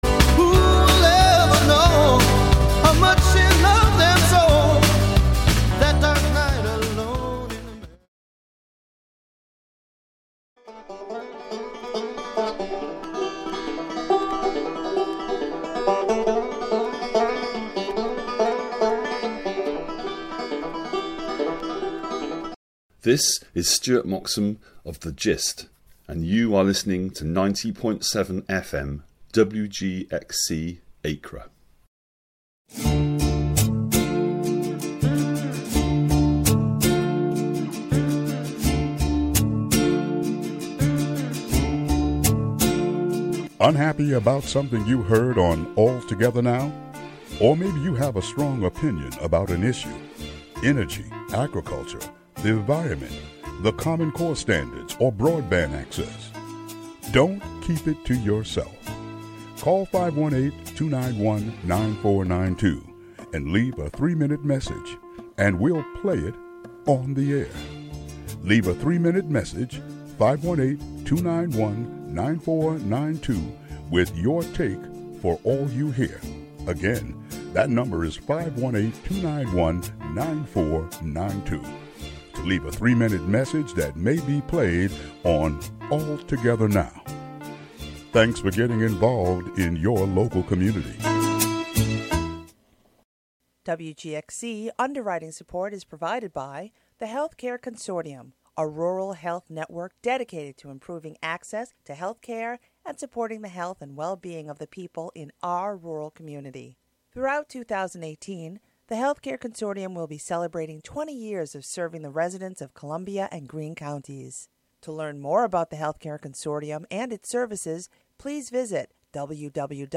A report from the Amateur Radio Relay League starts off the show after the local news headlines.
"All Together Now!" is a daily news show covering radio news, and news about the Hudson Valley.